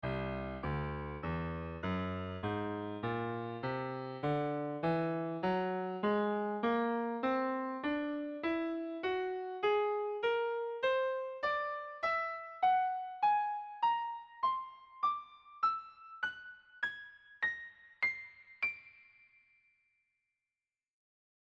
escala-de-tonos.mp3